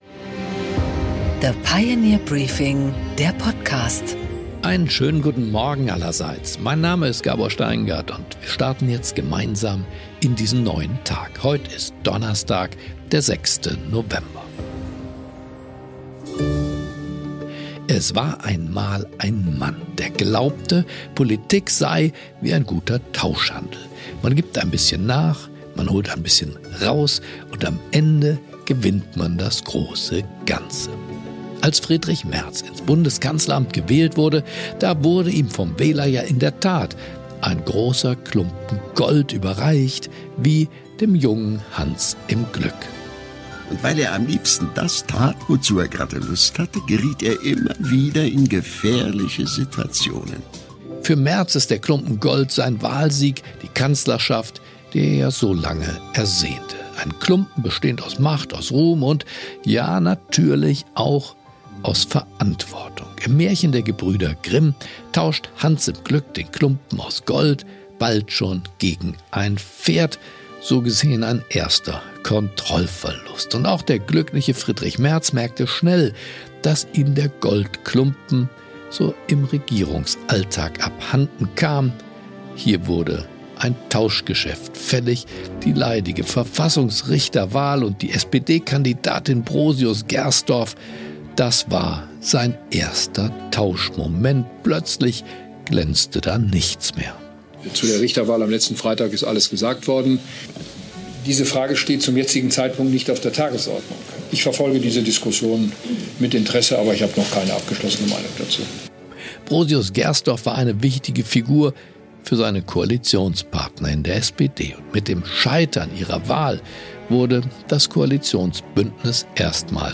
Gabor Steingart präsentiert das Pioneer Briefing
Im Gespräch: Ökonom Hans-Werner Sinn analysiert die tektonischen Verschiebungen in der globalen Ordnung.